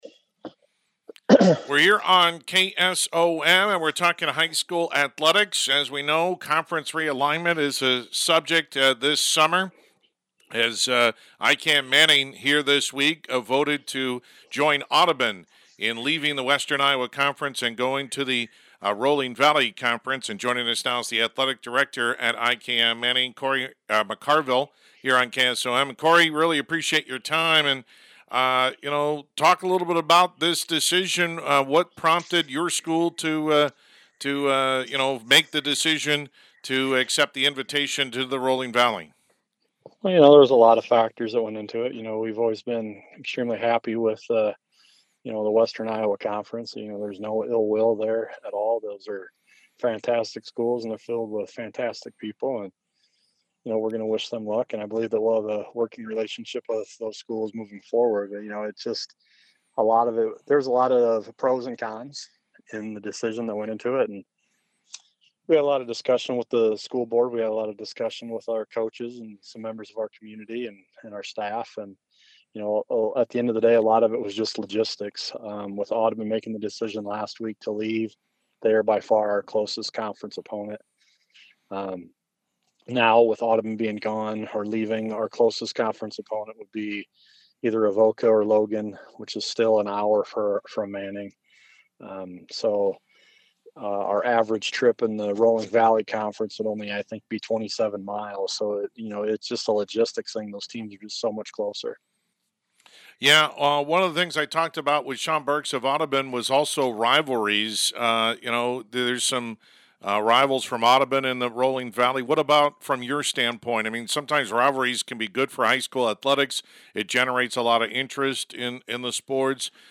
Complete Interview